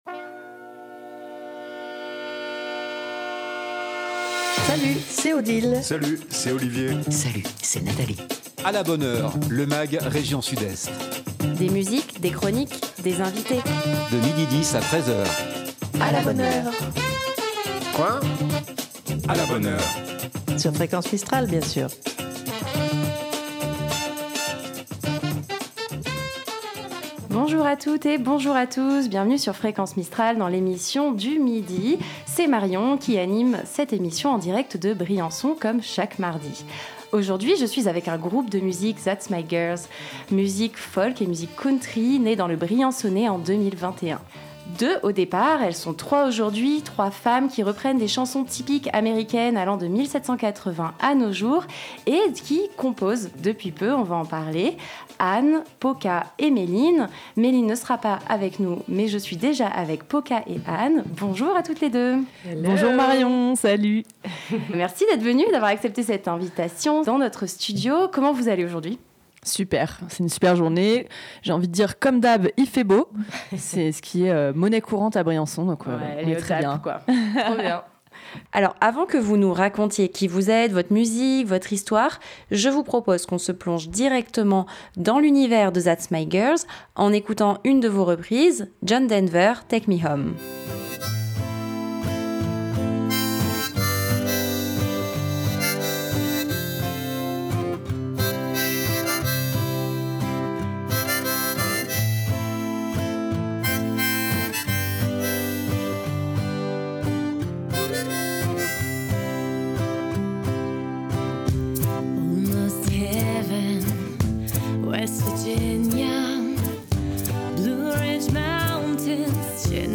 " Bienvenue dans le nouveau magazine région Sud-Est de Fréquence Mistral !
De Marseille à Briançon en passant par Manosque, sans oublier Dignes les bains, et Gap, un magazine régional, un Mag rien que pour vous, des invité.e.s en direct, des chroniques musique, cinéma, humour, littéraire, sorties et sur divers thèmes qui font l’actualité. Aujourd'hui nous accueillons le groupe That's my girls, groupe de musique country, né dans le briançonnais en 2021. Le duo devenu trio reprend des chansons typiques américaines allant de 1780 à nos jours, et compose depuis peu.